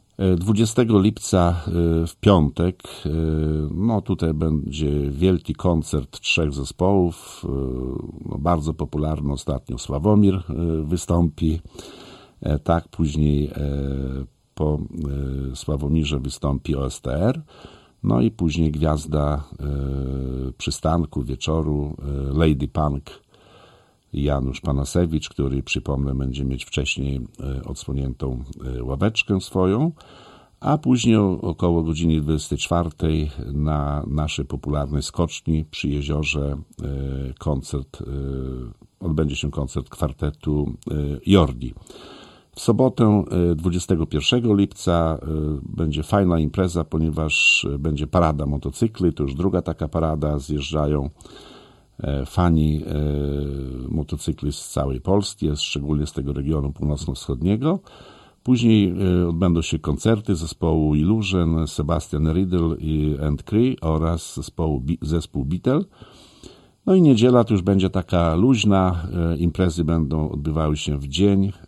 O szczegółach Wacław Olszewski, burmistrz Olecka.